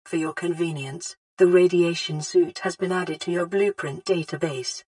RadSuitUnlockedVO.ogg